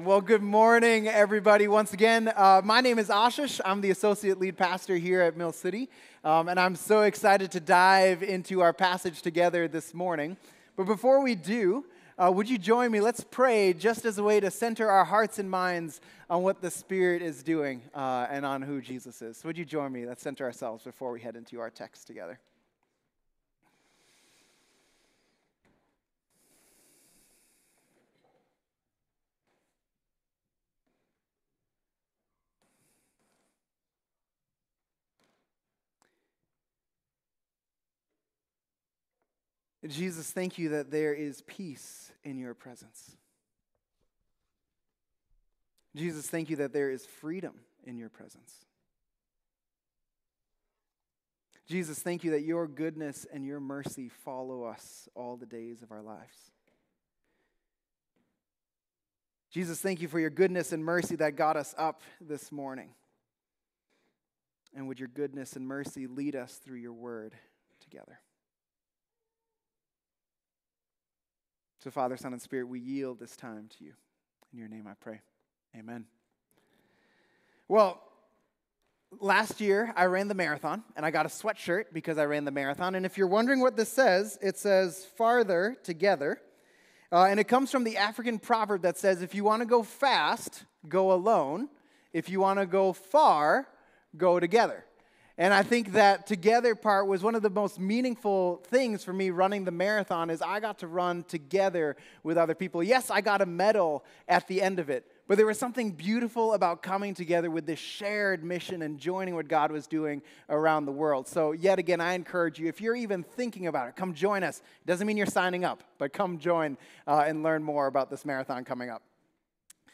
Preached by Pastor